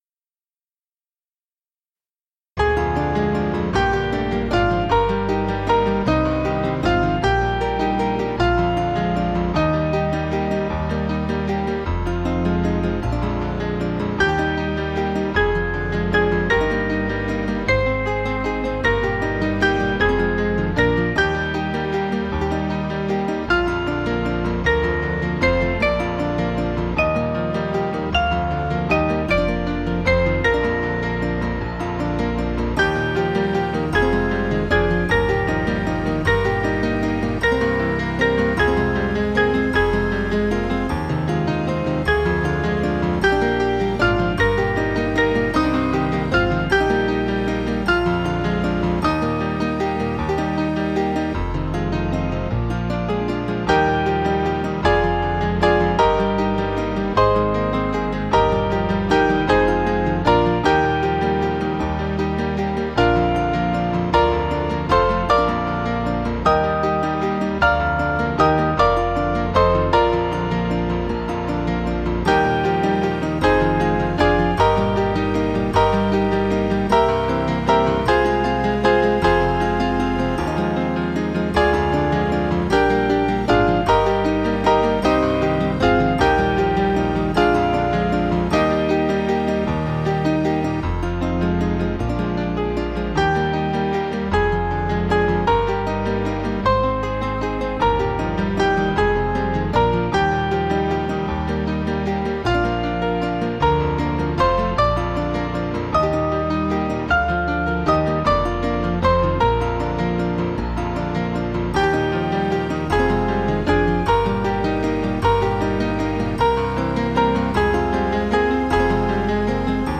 Small Band
(CM)   6/Eb 484kb